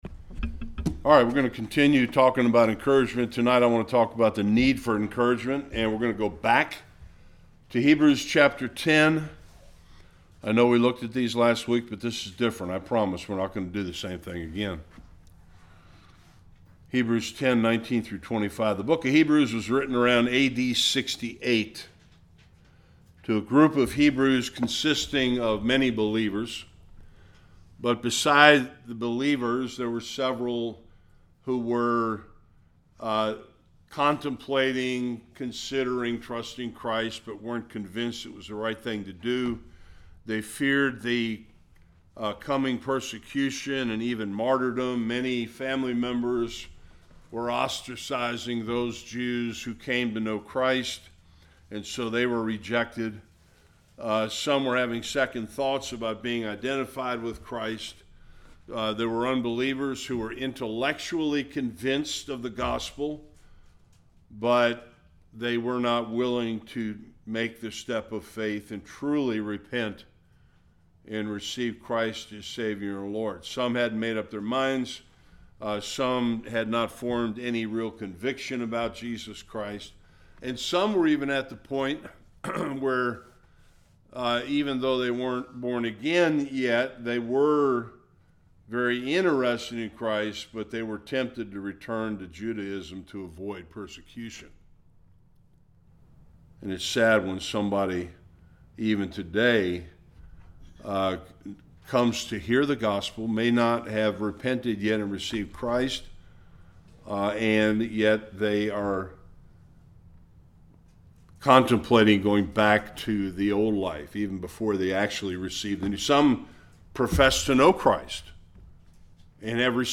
19-25 Service Type: Bible Study Encouragement includes both appreciation and affirmation.